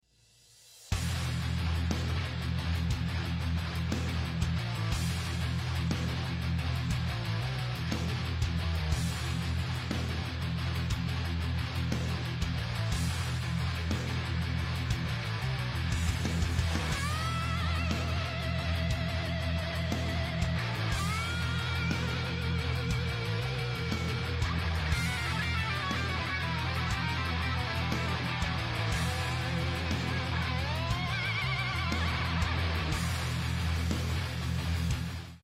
Cummins Big Cam 350 en sound effects free download